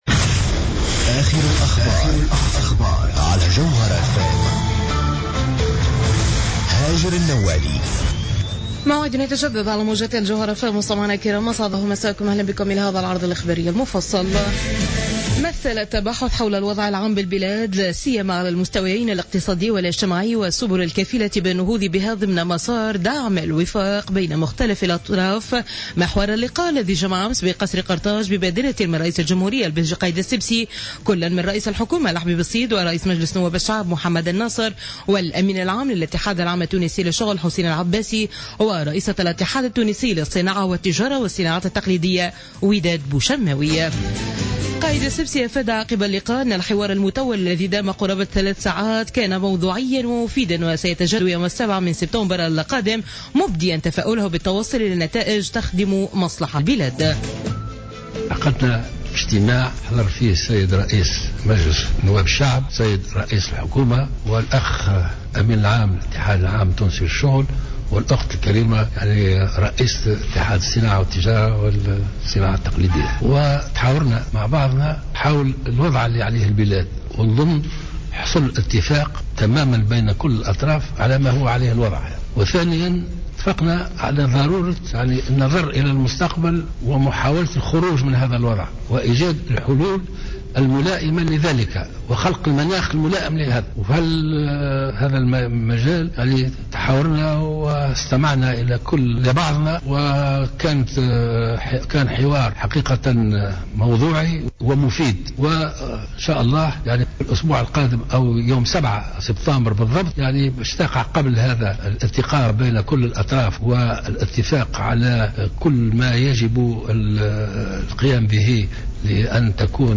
نشرة أخبار منتصف الليل ليوم الأربعاء 19 أوت 2015